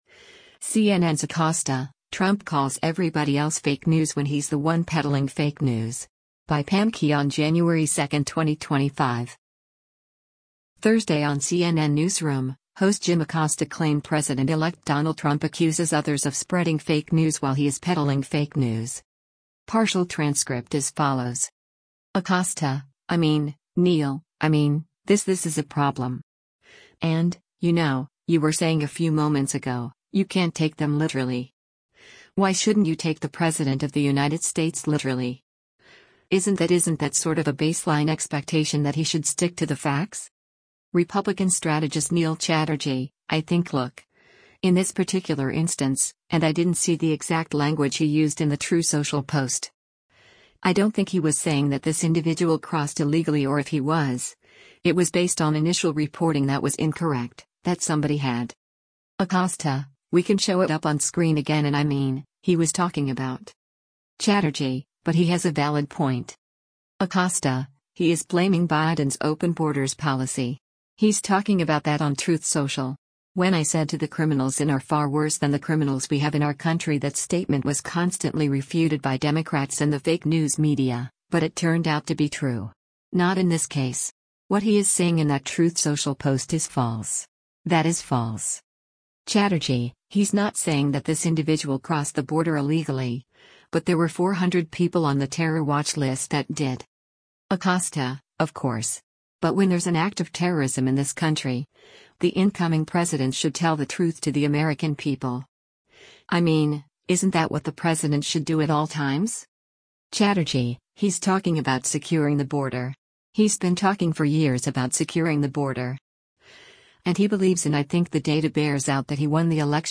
Thursday on “CNN Newsroom,” host Jim Acosta claimed President-elect Donald Trump accuses others of spreading fake news while he is “peddling fake news.”